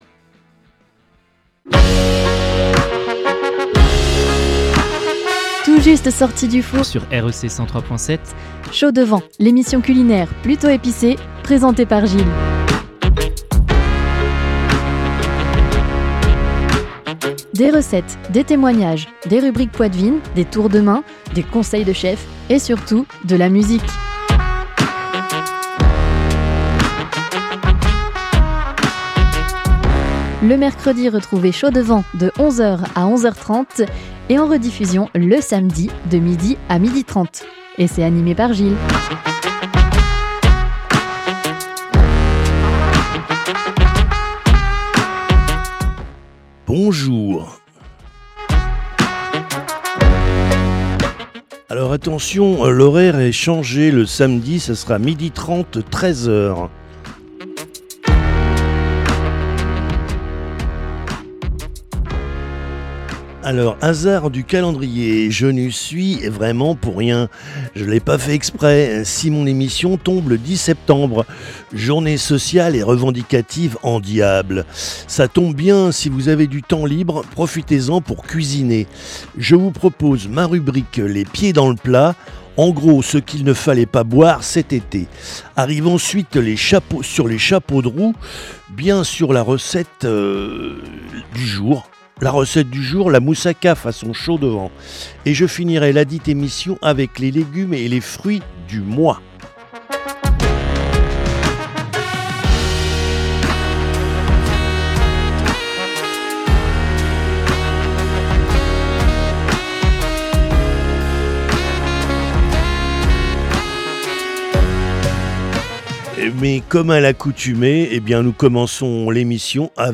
vous emmène dans les coulisses de la restauration, avec anecdotes, témoignages , rubriques , recettes avec des conseils de chef et forcément de la musique !